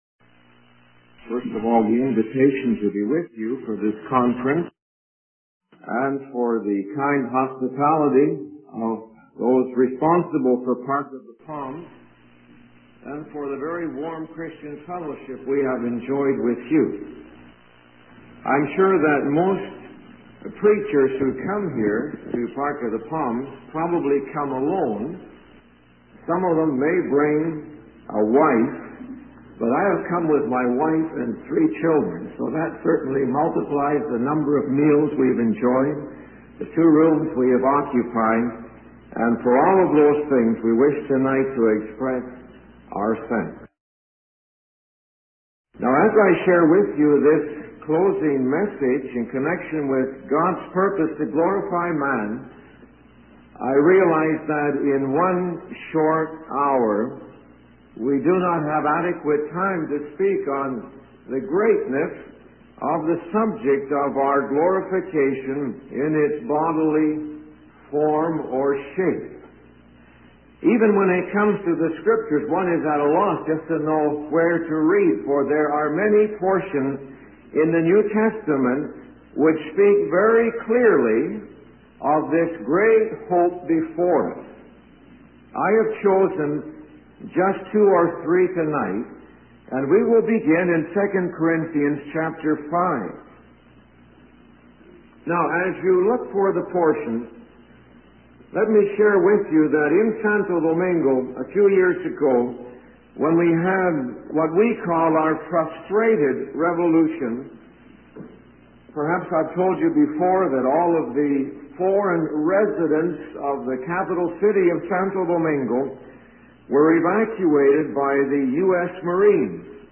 In this sermon, the speaker discusses the concept of the body bringing dishonor when it can no longer be controlled or used as desired.